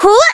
Taily-Vox_Jump.wav